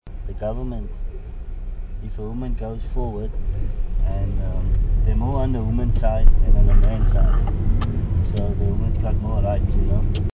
Taxi tales - Cape Town taxi drivers speak about feminism